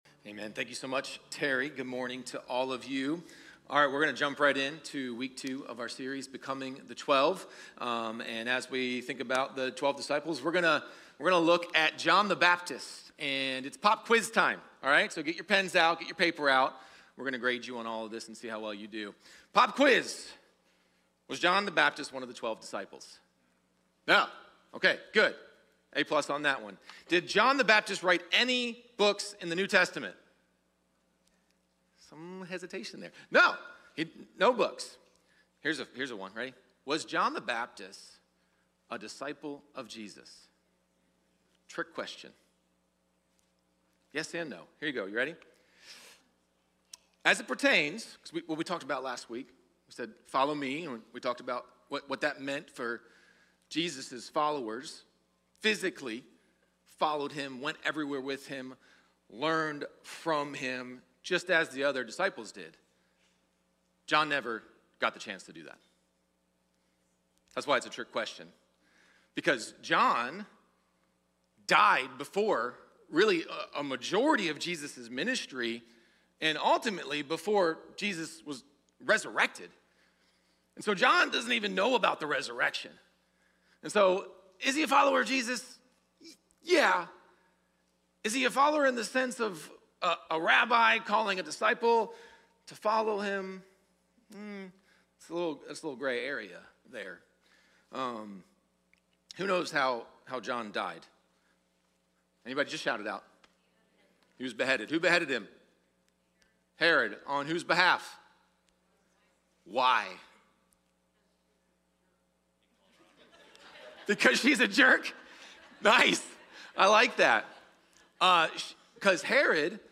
Sermons | Kairos Church